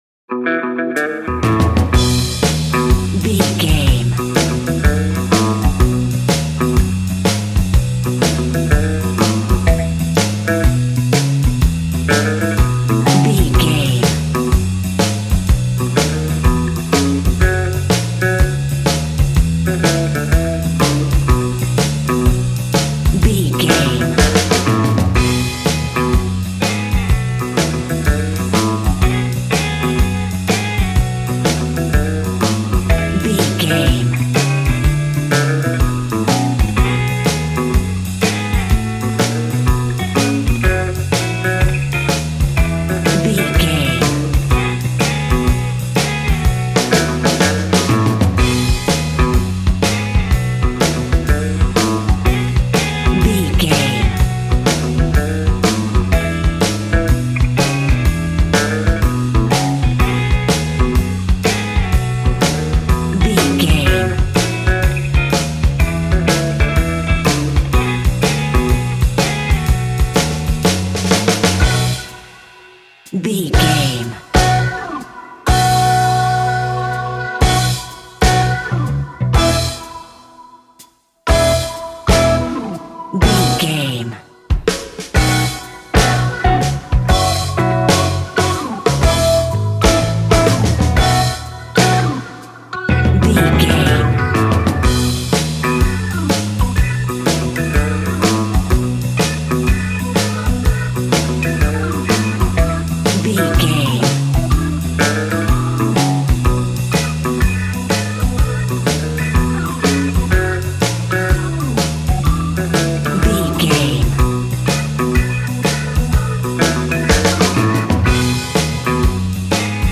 Ionian/Major
cheerful/happy
double bass
drums
piano
50s